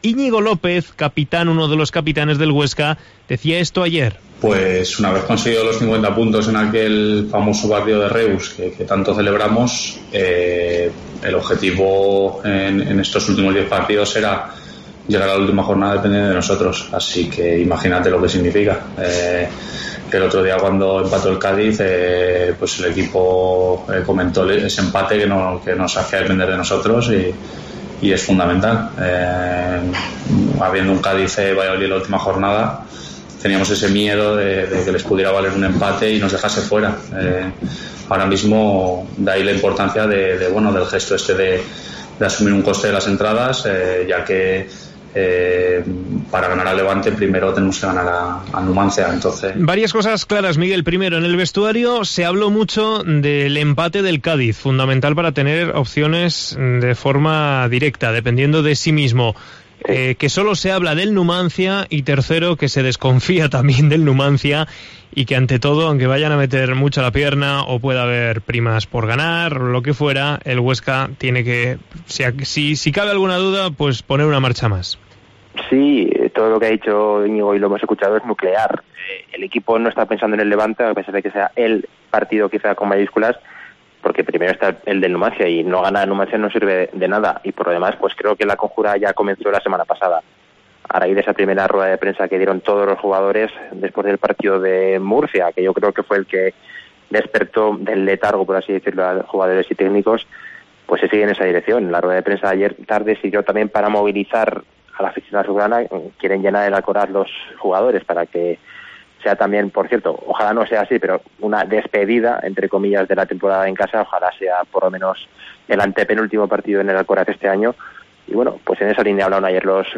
en sala de prensa